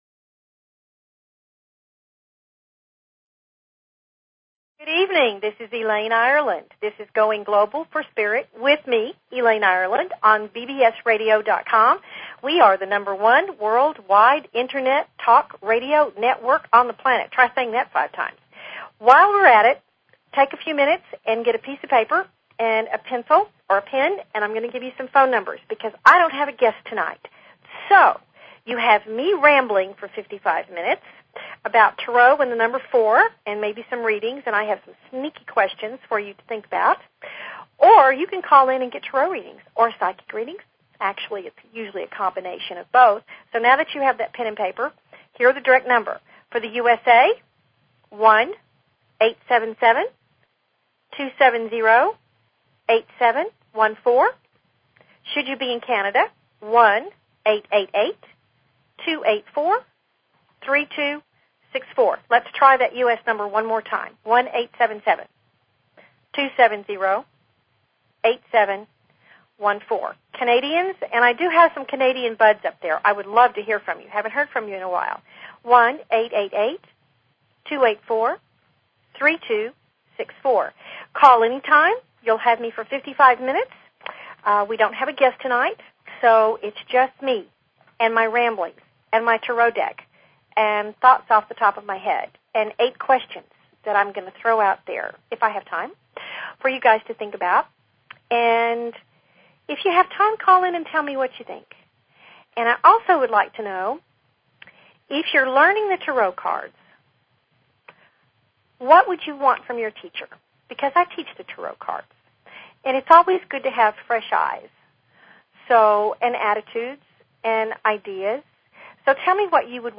Talk Show Episode, Audio Podcast, Going_Global_for_Spirit and Courtesy of BBS Radio on , show guests , about , categorized as
If loud noises and crowds are not your thing then join me for an hour of call in readings. In between I will be discussing what cards are important to July and the fourth.